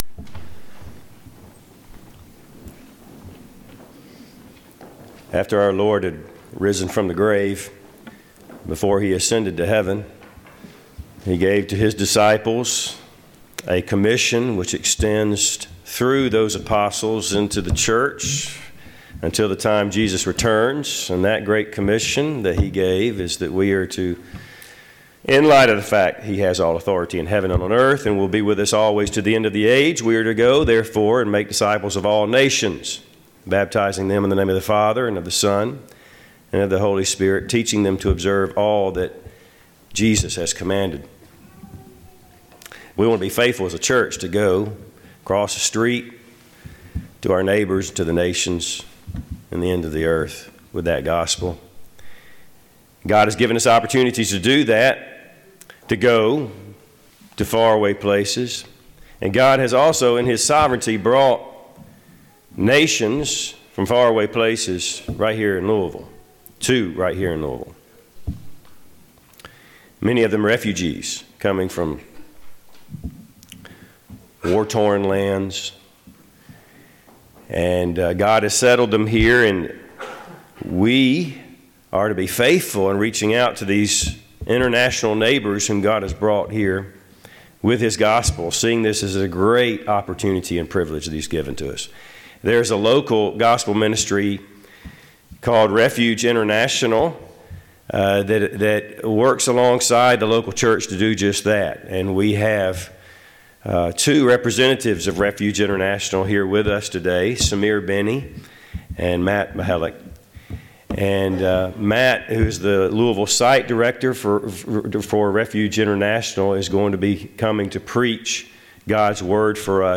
“Our Jerusalem” Festival sermon
Service Type: Sunday AM